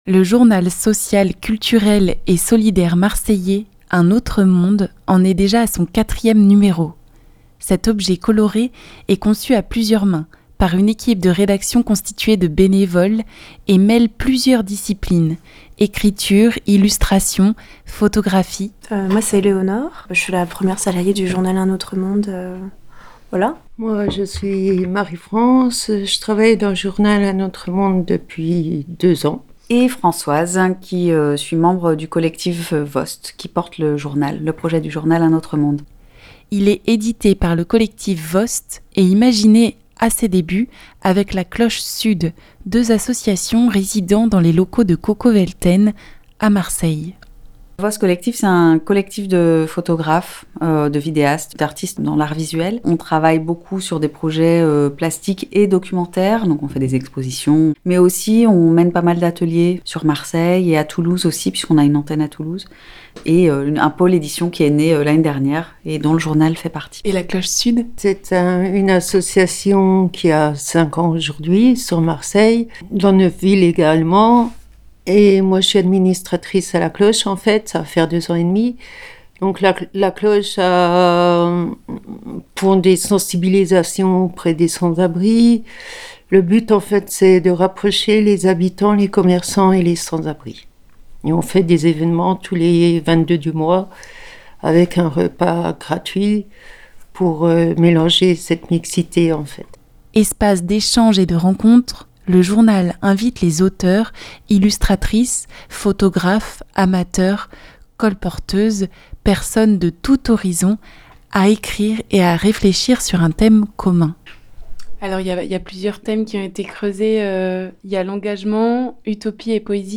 Ecoutons, trois des membres impliquées dans l'équipe du Journal, pour nous détailler le fonctionnement et les thématiques creusées.